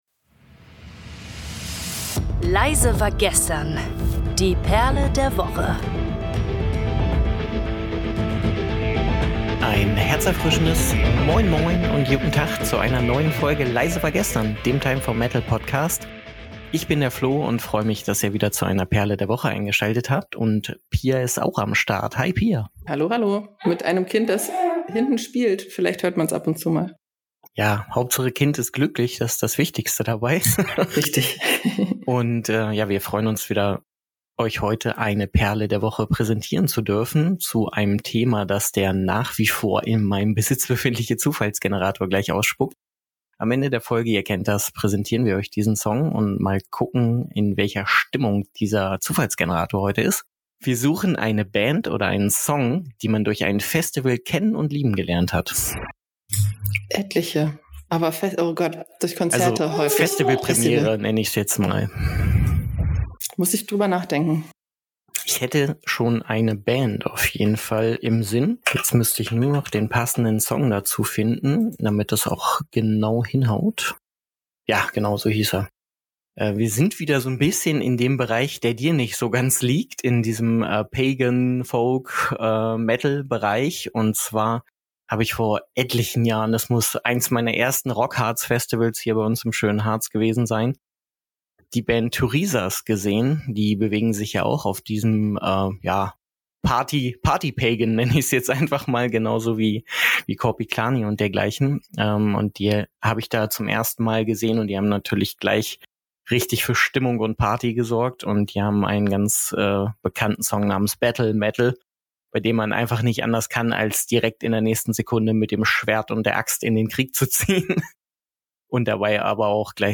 Am Ende wird der Song "Battlemetal" von Turisas vorgestellt.
Songs in der Episode: